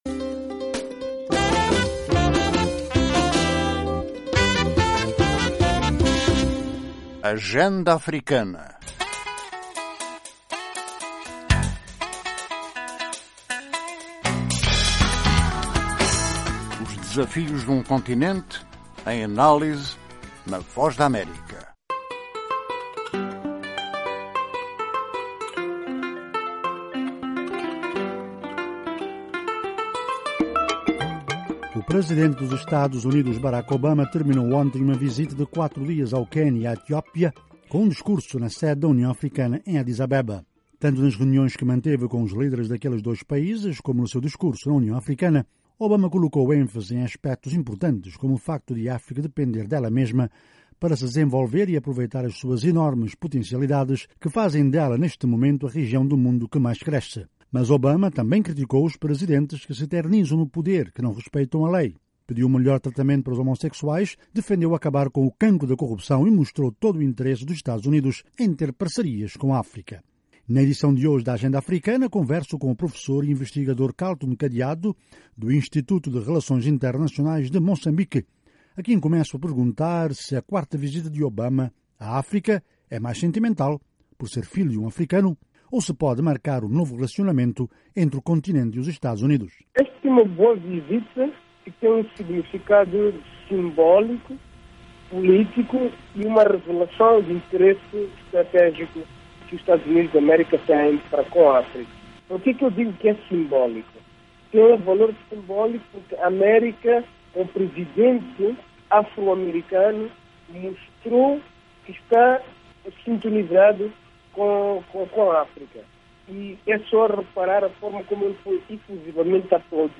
Análise